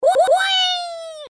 веселые
смешные